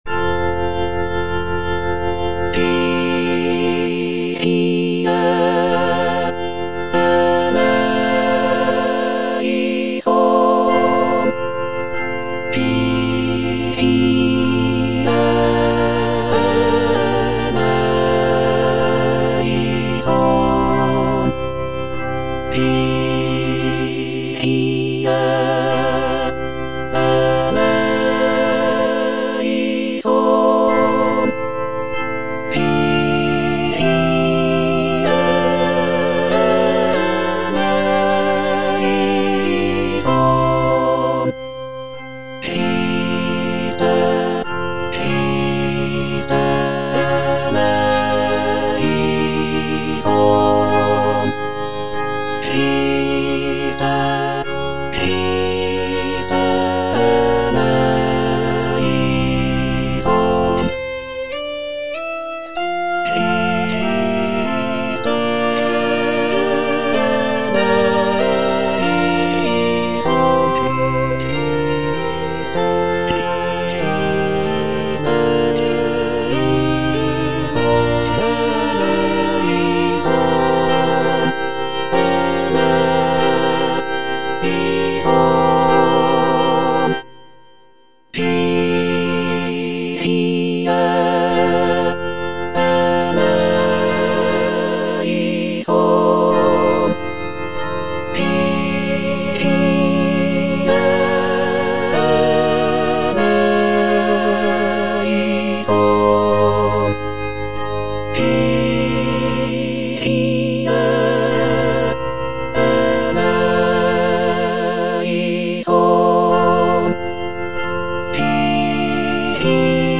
T. DUBOIS - MESSE IN F Kyrie (All voices) Ads stop: auto-stop
"Messe in F" is a choral work composed by Théodore Dubois in the late 19th century. It is a setting of the traditional Catholic Mass text in the key of F major. The piece is known for its lush harmonies, intricate counterpoint, and lyrical melodies. Dubois' use of contrasting dynamics and textures creates a dramatic and expressive work that showcases his skill as a composer.